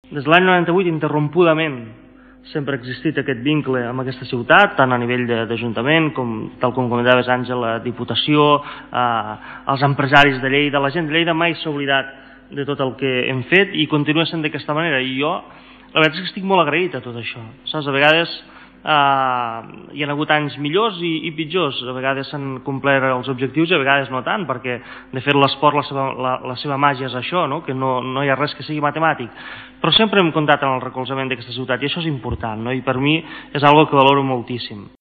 tall-de-veu-disidre-esteve